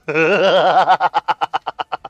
Risadinha